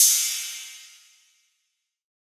Crashes & Cymbals
DDW Cymbal 2.wav